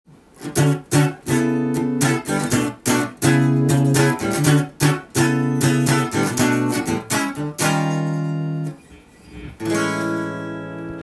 日本人が大好きなカラーの青いエレキギターです。
セミホロウボディーになっているので、箱鳴りする感じで
P－９０シングルコイルが入っている　ダンカンのファットキャットが搭載されています。
試しに弾いてみました
温かみのあるウォームな音がするので曲にぴったりですね！